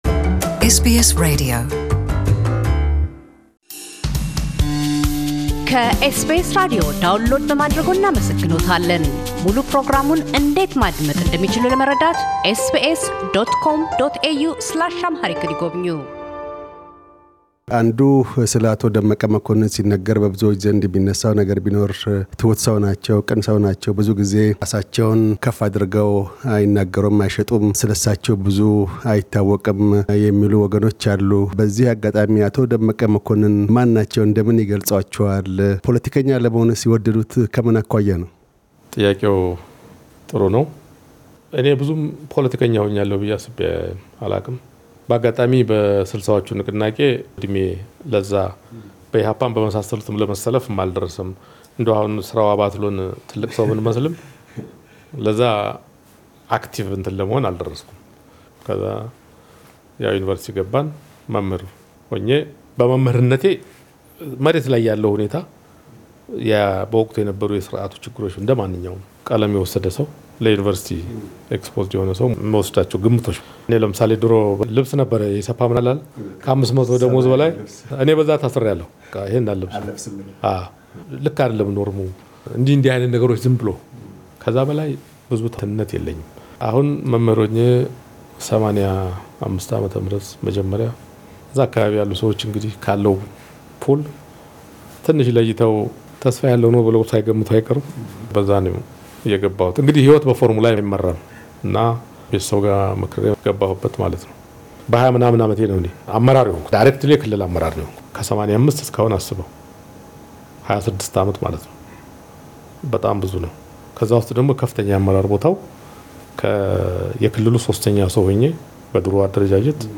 በክፍል ሁለት ቀጣይና መደምደሚያ ቃለ ምልልሳችን፤ ምክትል ጠቅላይ ሚኒስትር ደመቀ መኮንን ከመምህርነት ወደ ፖለቲካው ዓለም እንደምን ተስበው እንደዘለቁ፤ ወደ ቁጥር ሁለት የሥልጣን እርከን ላይ እንዴት እንደደረሱ፤ በምን ሳቢያ “አባ መላ” እንደተሰኙ፤ ባለፉት ሶስት የለውጥ ንቅናቄ ዓመታት የነበሩትን ፈታኝ ሁኔታዎች፣ የእሳቸውን መንታ መንገድ ላይ መድረስና ሚና አንስተው ይናገራሉ።